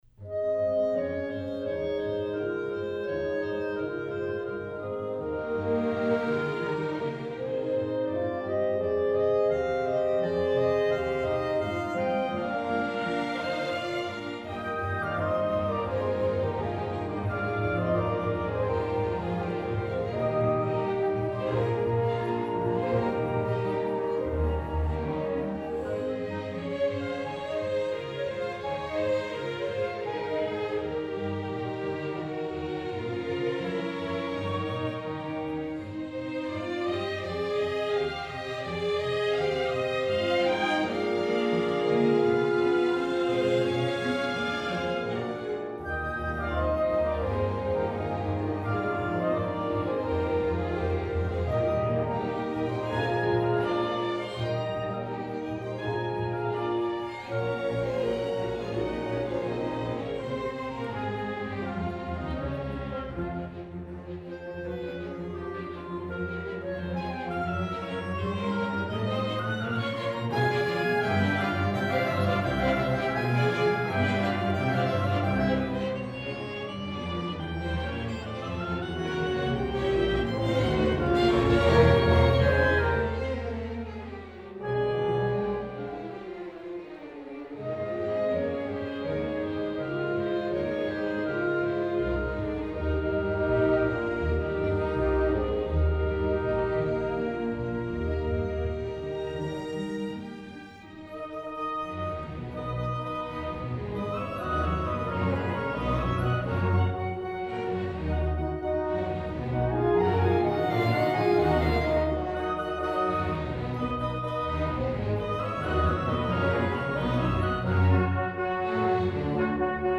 Professionally Edited Recordings